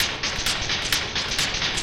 RI_DelayStack_130-03.wav